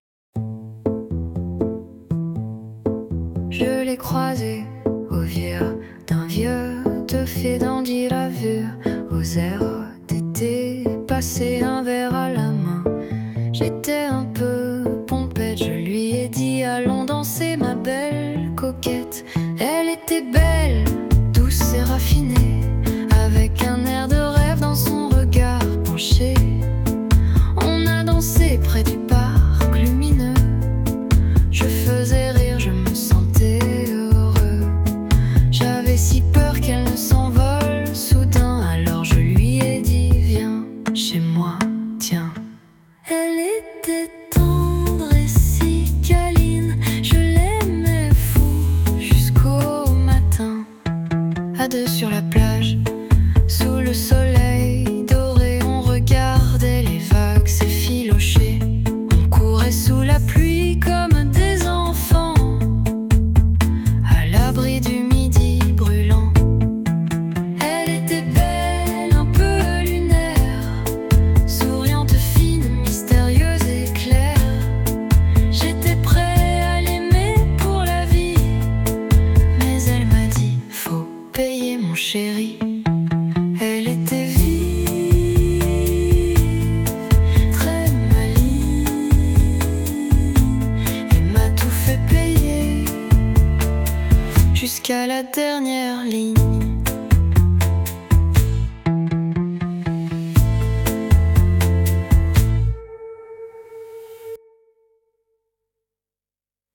• Исполняет: V4.5 Fusion
• Аранжировка: V4.5 Fusion
• Жанр: Поп